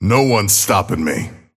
Abrams voice line - No one's stopping me.